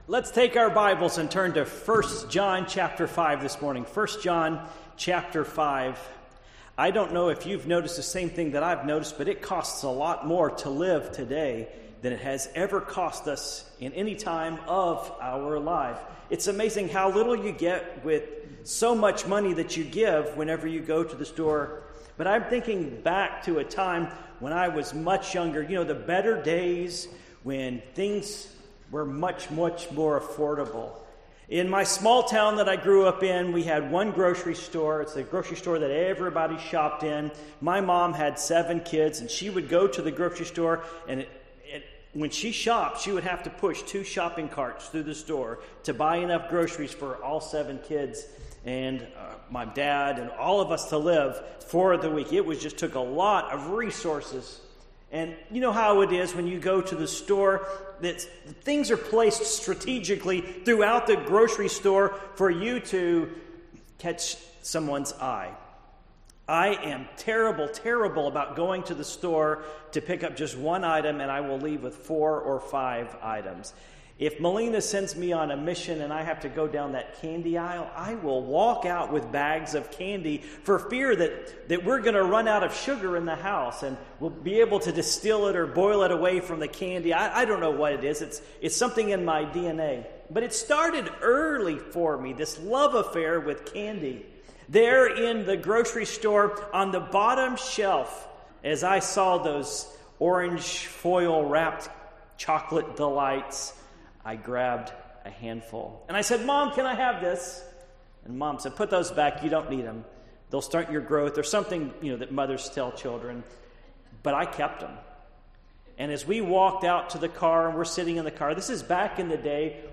Passage: 1 John 5:12-21 Service Type: Morning Worship